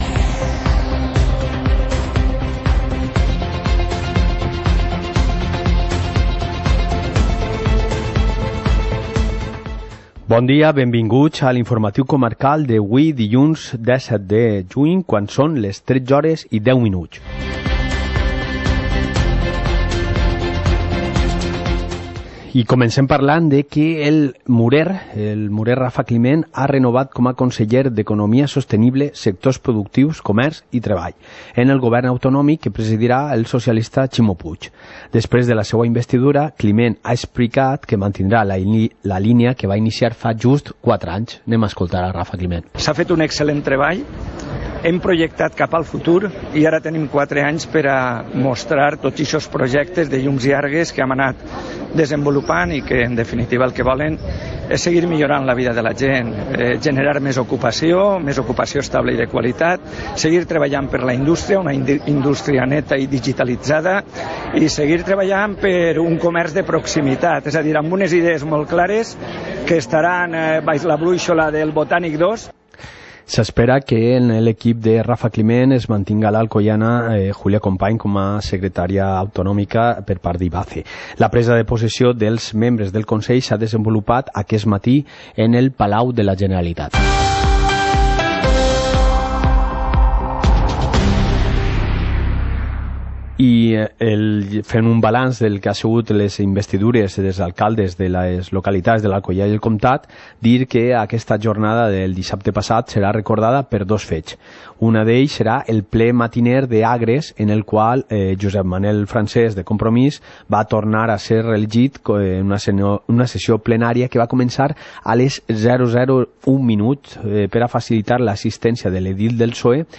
Informativo comarcal - lunes, 17 de junio de 2019